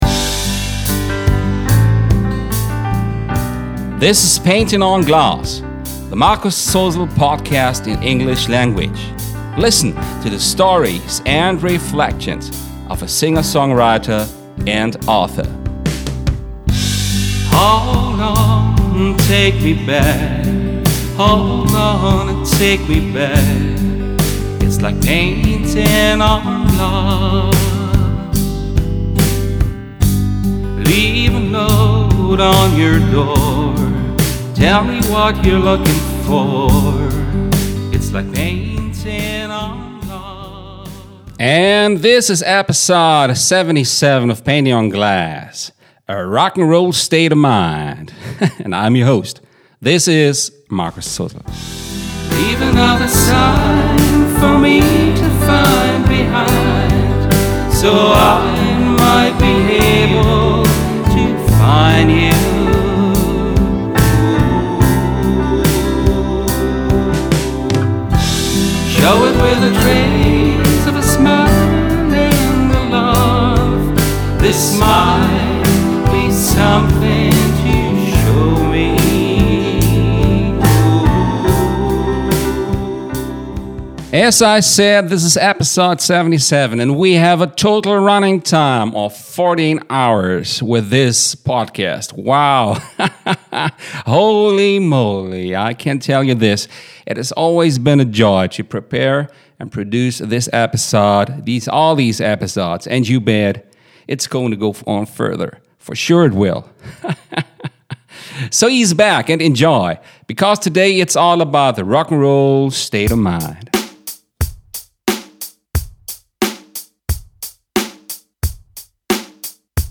Enjoy while he is telling you with a smile - or a twinkle in his eye.